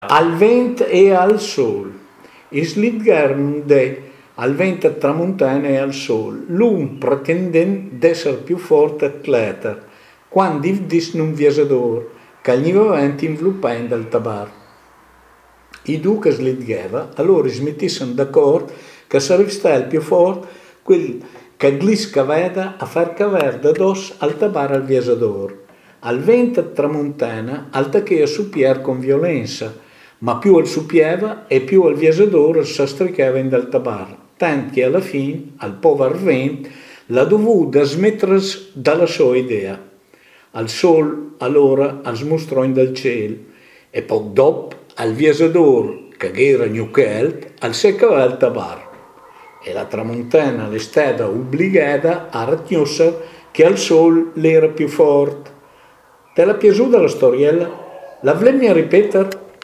Una storiella esopica nel dialetto di Mirandola